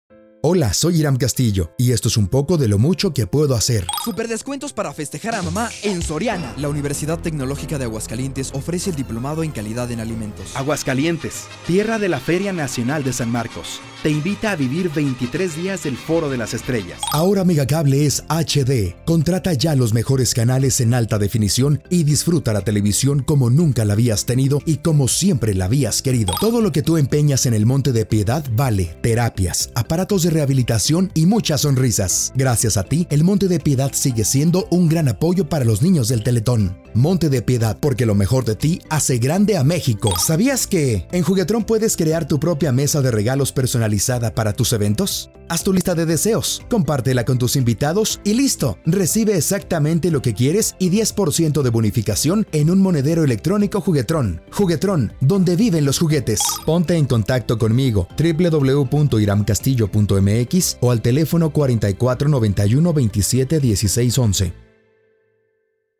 Locutor de cabina, Locutor comercial, Conductor de eventos, Maestro de ceremonias.
Voice Over,Locutor en Español de México, mas de 20 años de experiencia.
Sprechprobe: Werbung (Muttersprache):